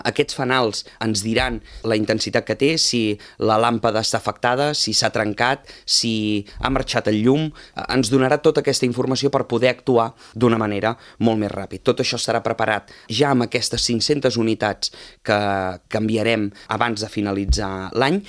Així ho ha anunciat el tinent d’alcaldia de Serveis públics, Josep Grima, a l’ENTREVISTA POLÍTICA de Ràdio Calella TV, l’espai setmanal amb els portaveus polítics municipals.